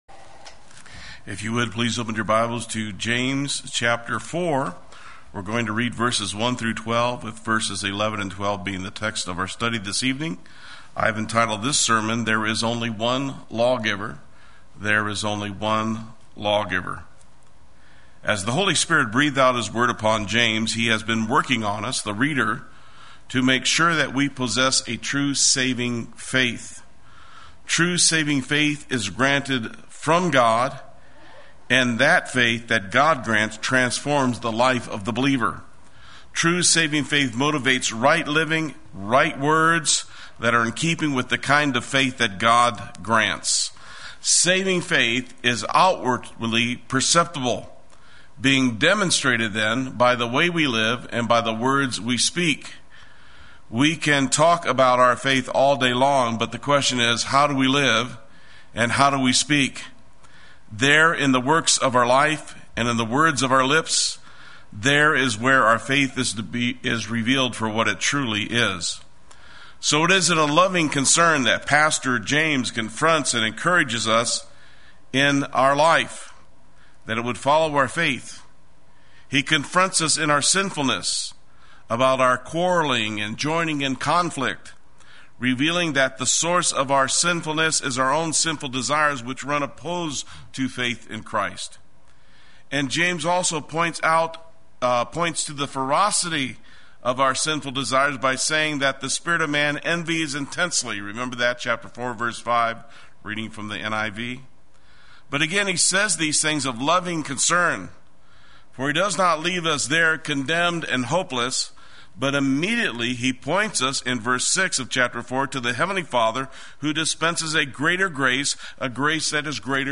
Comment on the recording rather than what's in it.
There is Only One God Wednesday Worship